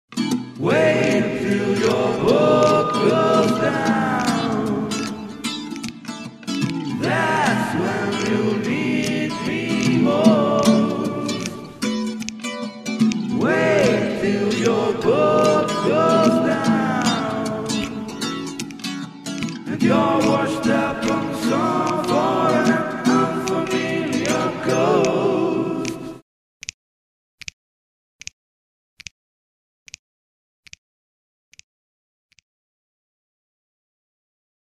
guitar, keyboards and voices
drums and bass